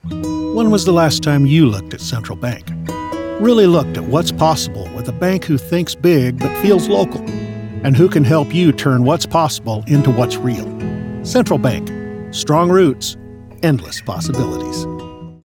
Male
15 Second Banking Commercial
Words that describe my voice are Storyteller, Conversational, Relatable.